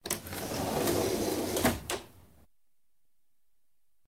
doorsopen.ogg